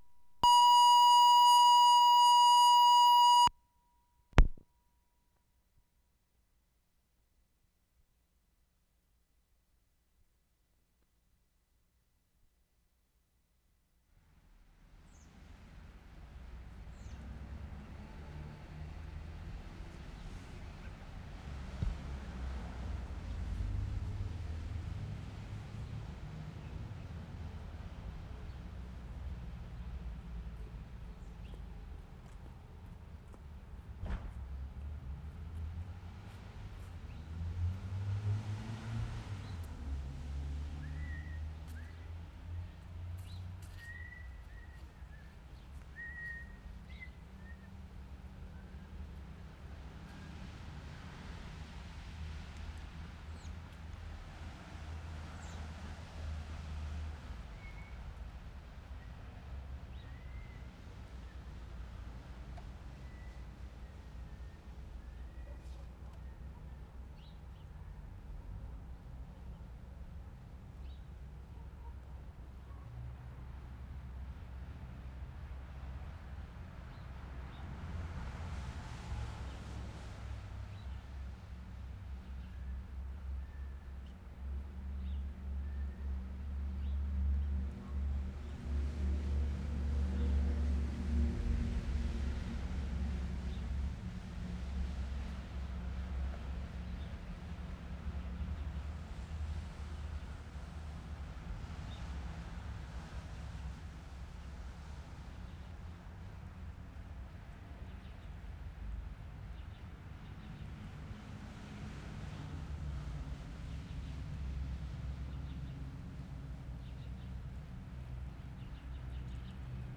WORLD SOUNDSCAPE PROJECT TAPE LIBRARY
SUMMERSIDE, P.E.I. Oct. 18, 1973
GENERAL NEIGHBOURHOOD AMBIENCE 2'37"
Throughout first minute kid whistling, otherwise not too interesting, mainly traffic.